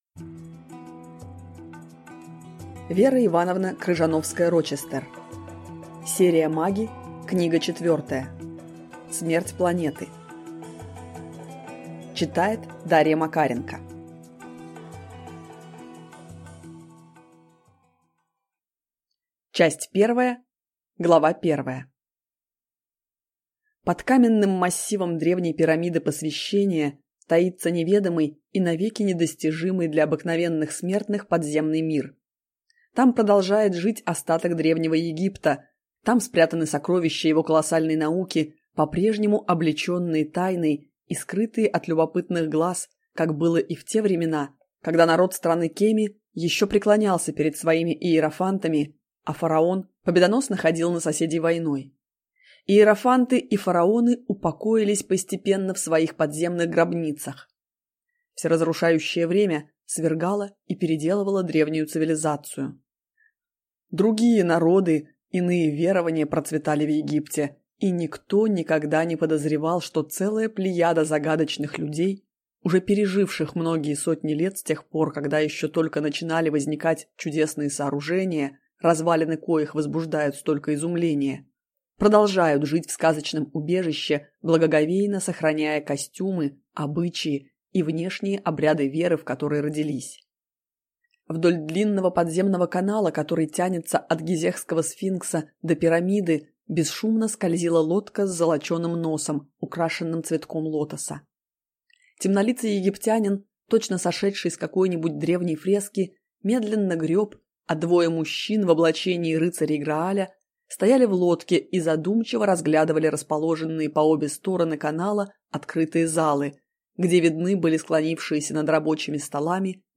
Аудиокнига Смерть планеты | Библиотека аудиокниг